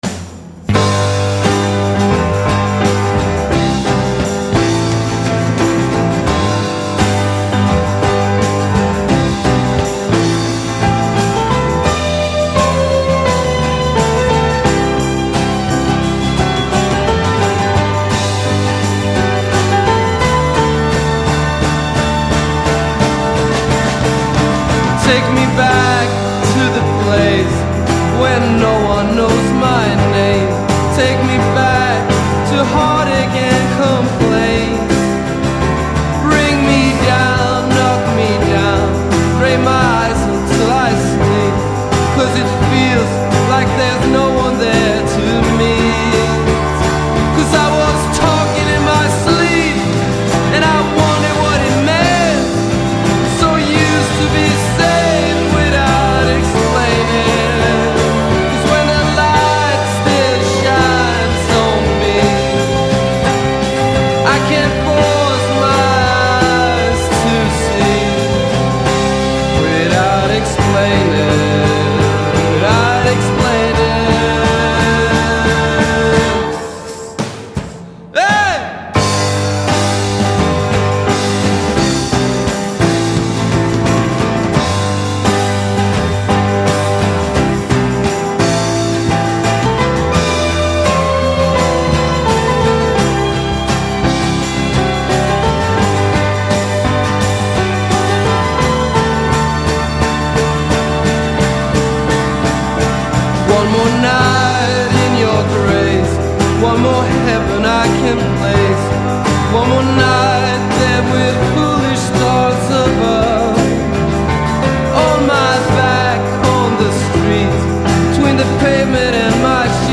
cascate di violini e arrangiamenti in abito da sera.